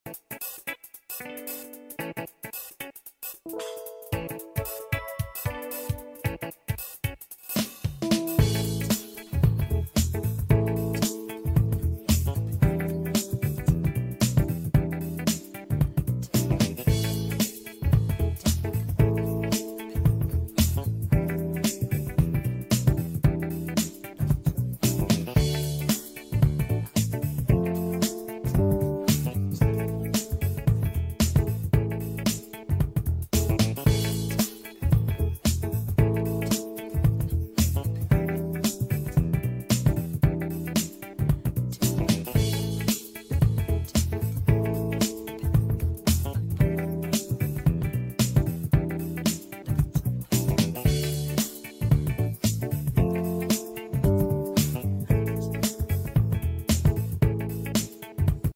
Karaoke track